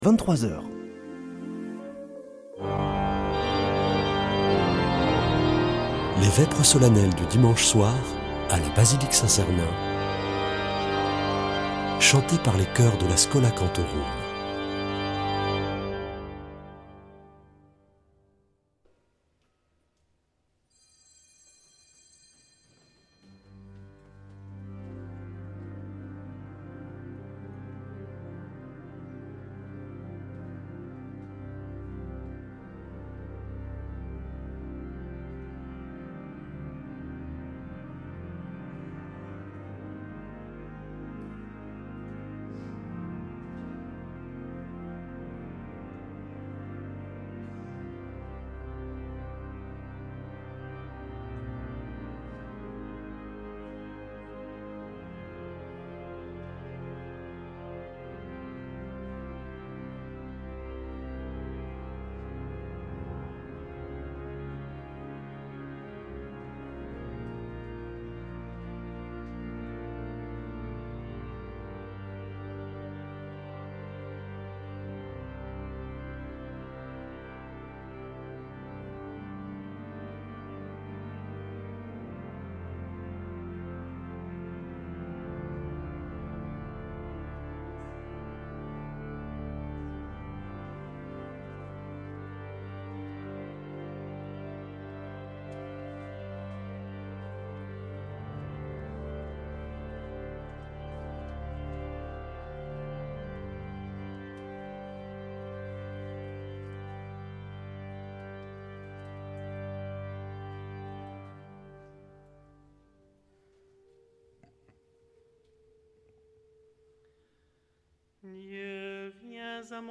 Vêpres de Saint Sernin du 20 oct.